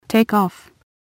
こちらは つづりを入力すると、その通りに話してくれるページです。
そこでAPR9600のAnalogInputにPCのLINEOUTを接続したところ、 適度な音量で再生ができるレベルになりました。
ホールドスイッチを解除したときの音声